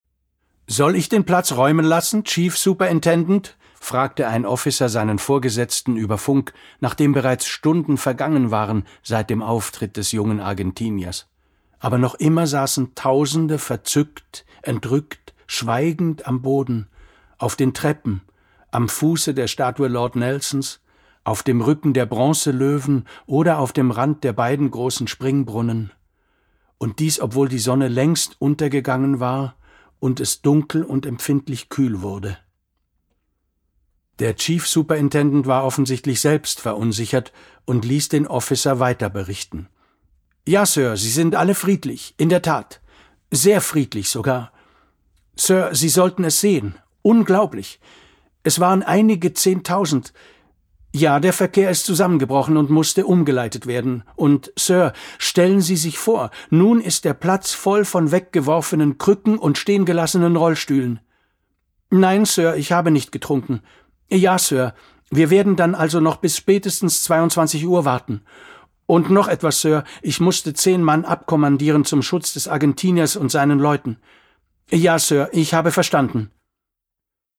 Hörbuch
Verschaffe Dir einen Eindruck durch einige Ausschnitte des Hörbuchs...